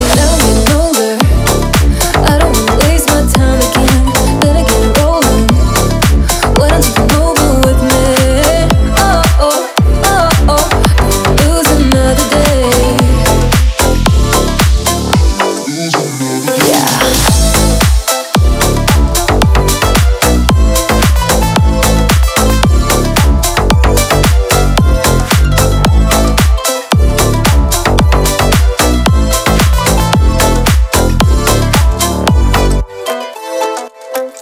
• Качество: 320, Stereo
поп
ритмичные
громкие
красивый женский голос